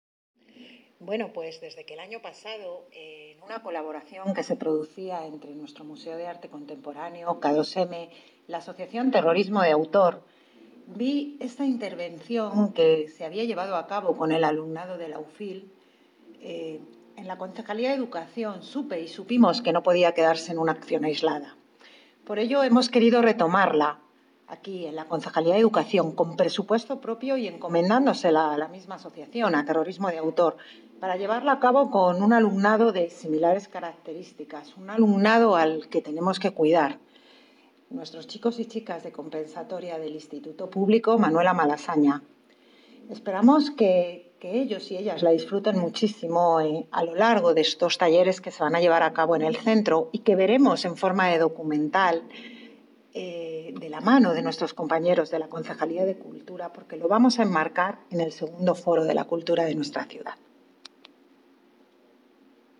Audio - Isabel Cruceta (Concejala de Educación) Sobre proyecto Máscaras